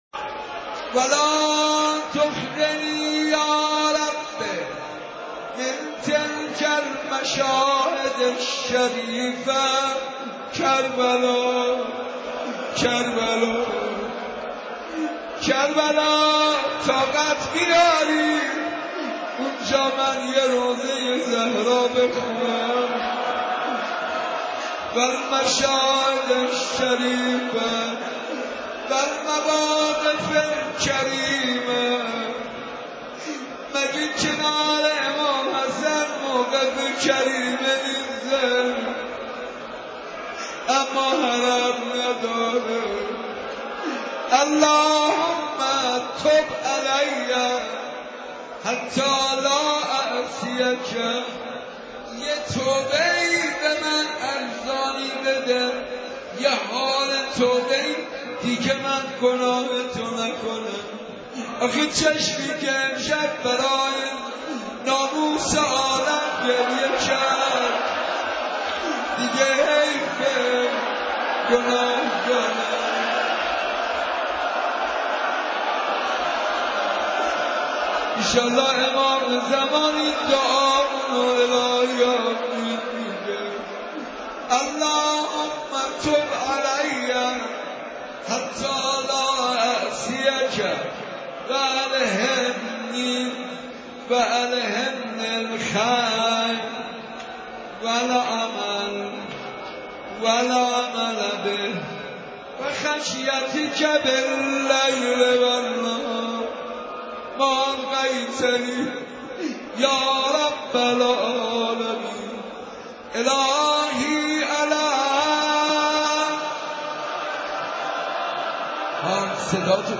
رمضان شب هفتم